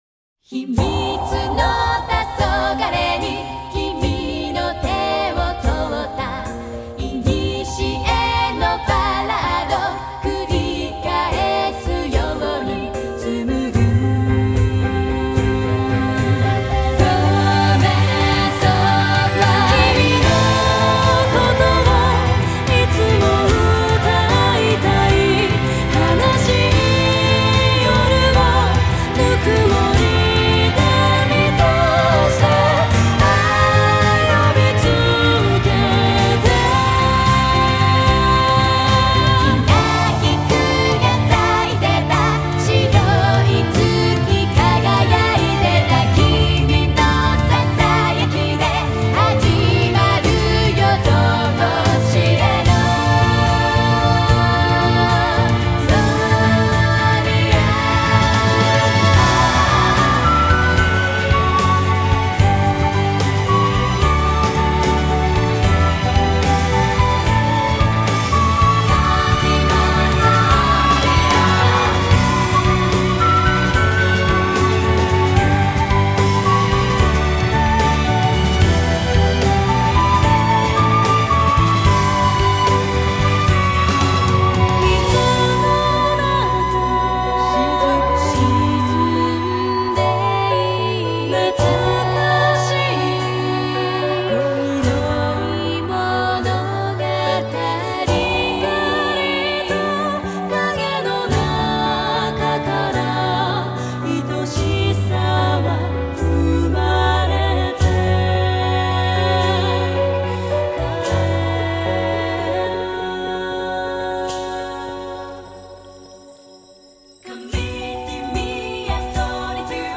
Genre: J-Pop, Female Vocal